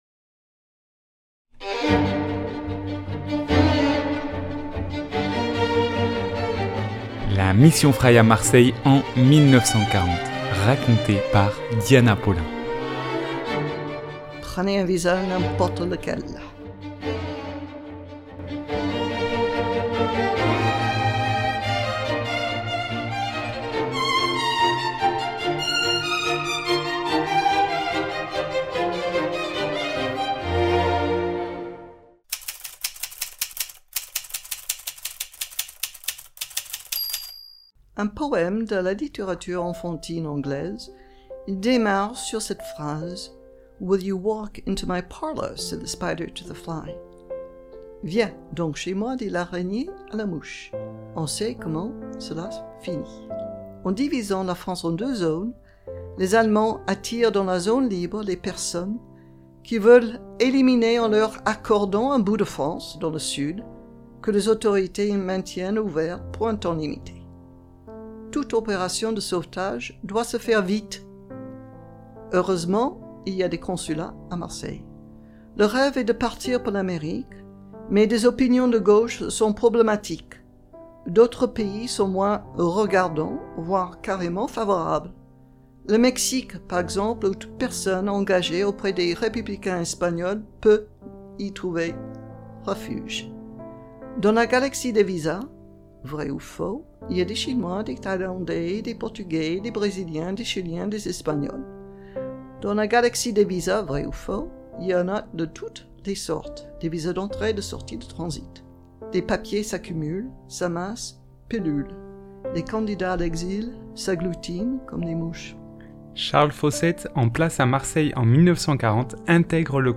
5 - Prenez un visa n'importe lequel (amazing grace piano).mp3 (2.37 Mo)